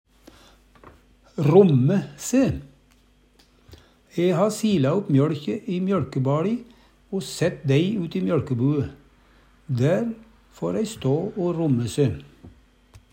romme se - Numedalsmål (en-US)